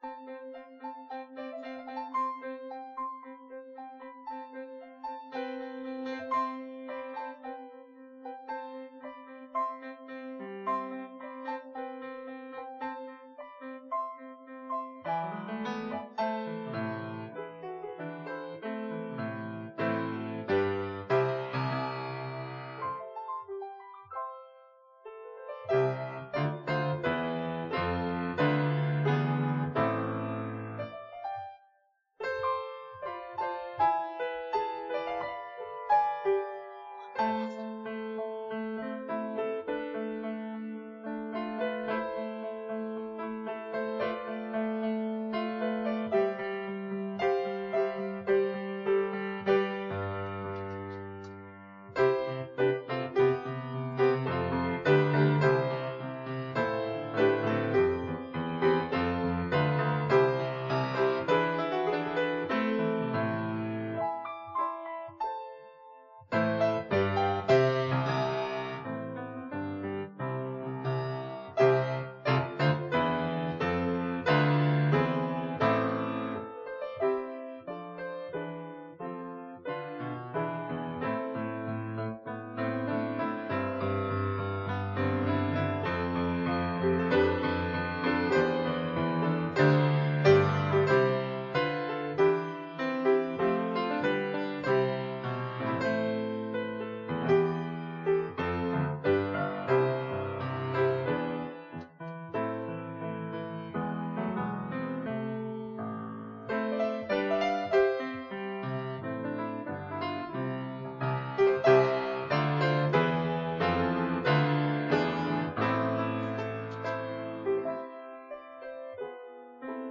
Fellowship Church is pleased to offer this live service at 10AM.